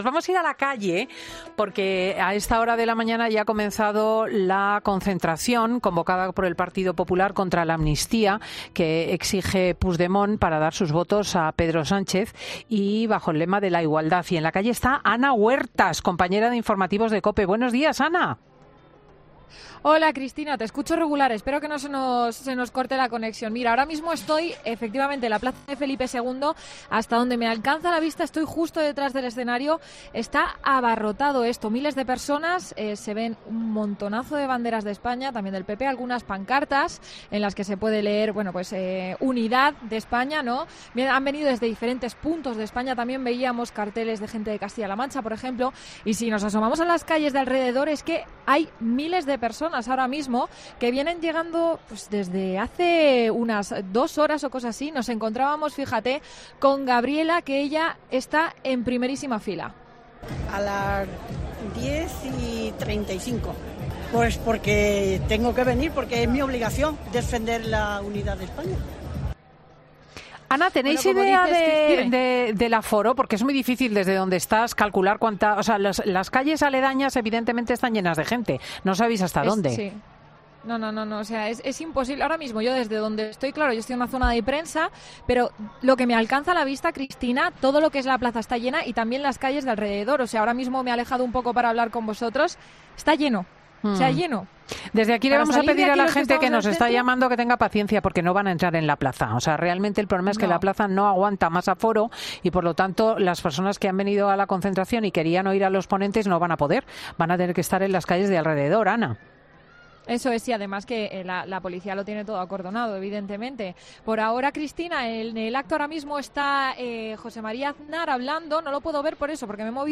Gritos de unidad, pancartas y calles repletas: así se ha vivido la manifestación contra la amnistía
Estamos en la plaza de Felipe II para tomar el pulso al acto del Partido Popular en Madrid donde el lema es "frente a la amnistía, igualdad"